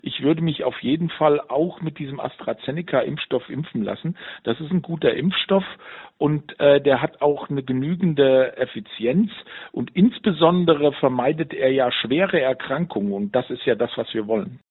Virologe